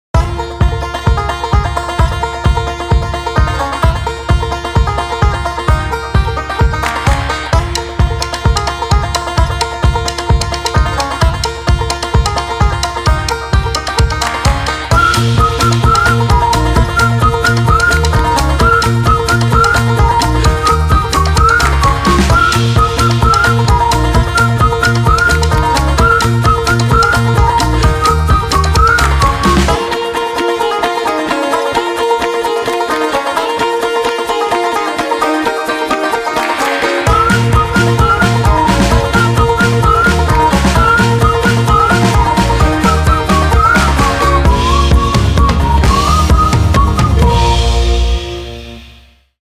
FAST INDIE FOLK WITH BANJO
Energy / Positive / Whistles / Acoustic